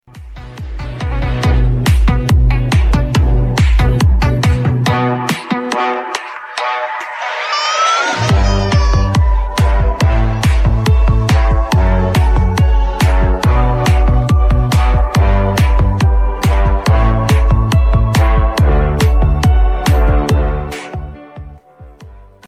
Baggrundsmusik